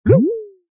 ring_miss.ogg